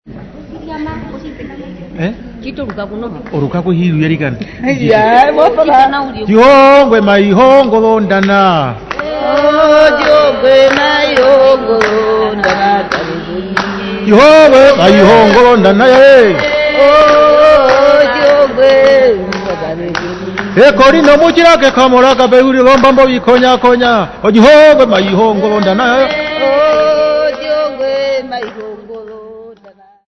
1 man and 4 ladies
Herero Folk
Choral music
Field recordings
Africa Namibia Walvis Bay f-sx
Indigenous music.
7.5 inch reel